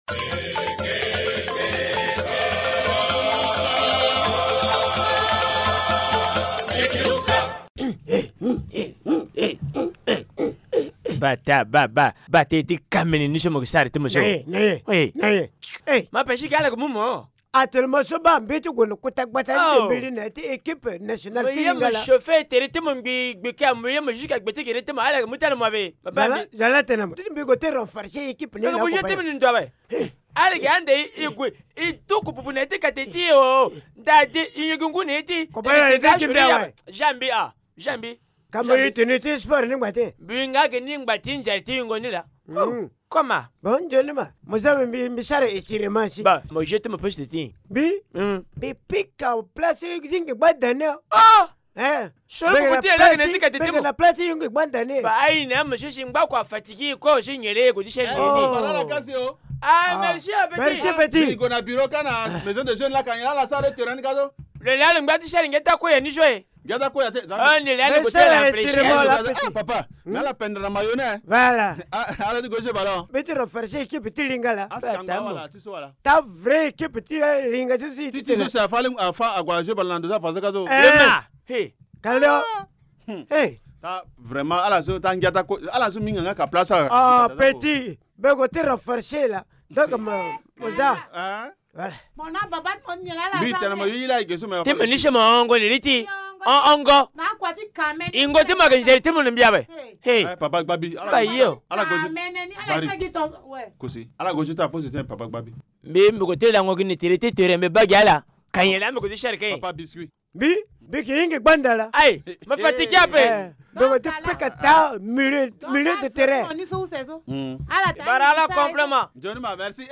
Linga théâtre : la situation de l’équipe de football du village inquiète les sages